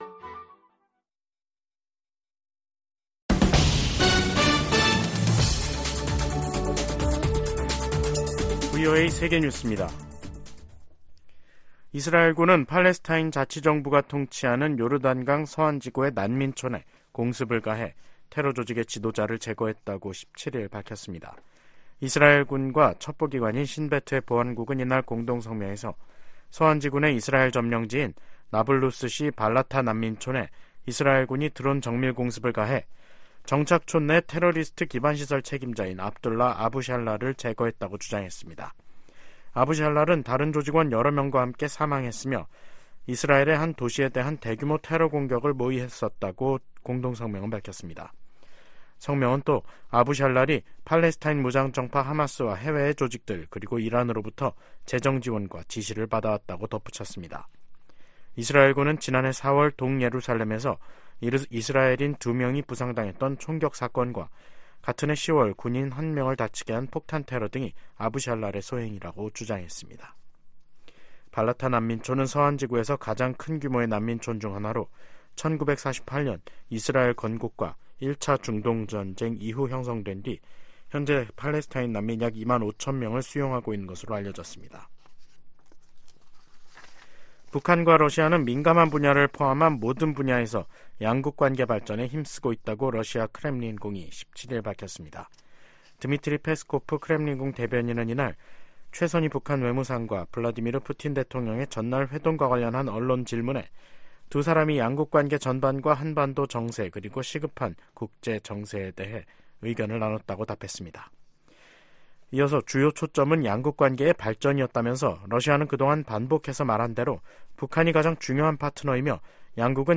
VOA 한국어 간판 뉴스 프로그램 '뉴스 투데이', 2024년 1월 17일 3부 방송입니다. 블라디미르 푸틴 러시아 대통령이 모스코바에서 최선희 북한 외무상을 만났습니다.